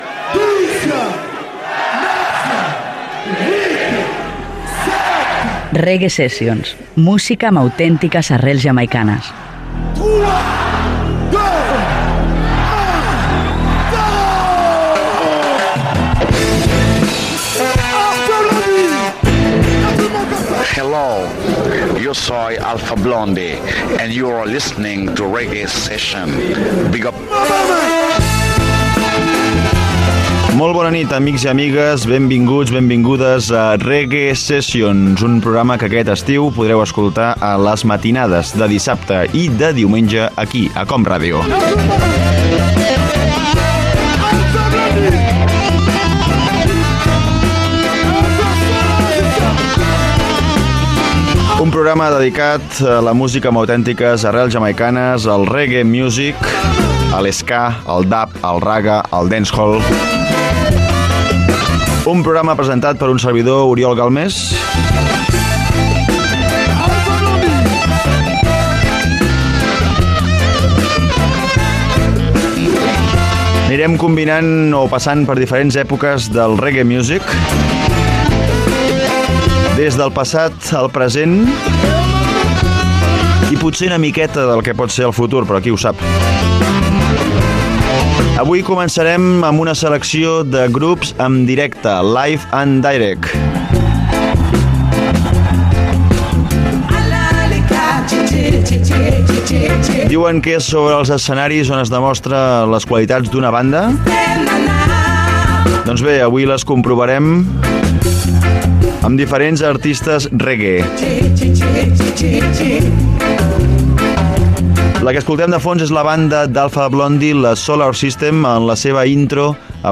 Careta del programa, presentació del programa dedicat a les músiques amb arrels jamaicanes
Musical